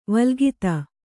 ♪ valgita